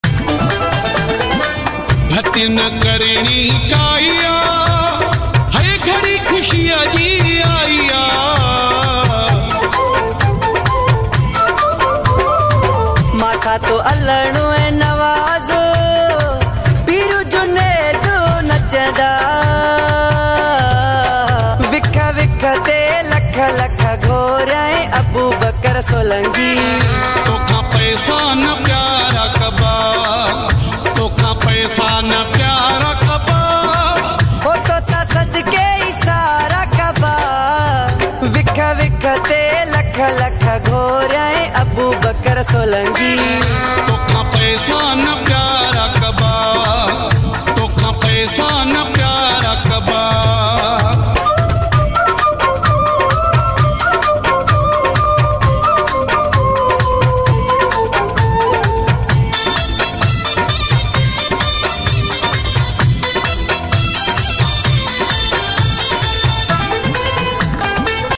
New Wedding Sindhi Mashup Song